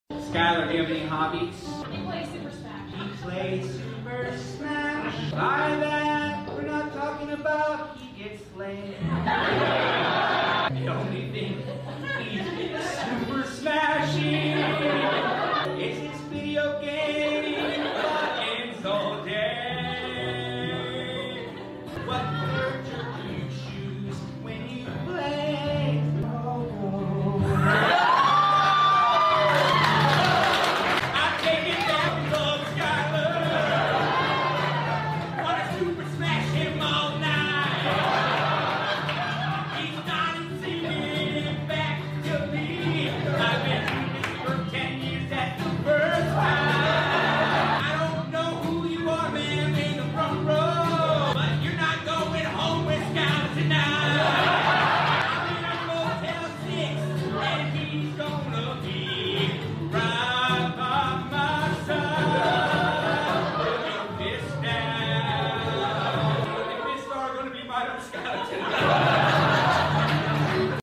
Audience member sings back to me